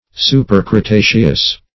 Search Result for " supercretaceous" : The Collaborative International Dictionary of English v.0.48: Supercretaceous \Su`per*cre*ta"ceous\, a. (Geol.)